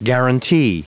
Prononciation du mot guarantee en anglais (fichier audio)
Prononciation du mot : guarantee